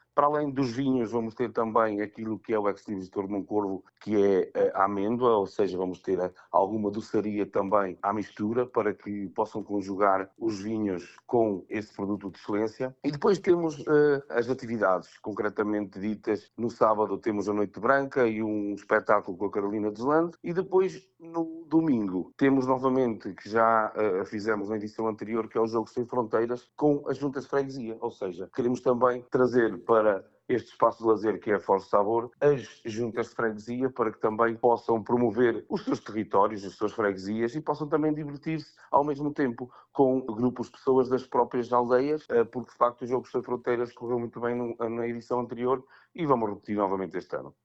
Para além dos vinhos, o autarca destaca que vão estar evidência os produtos endógenos, como a amêndoa coberta: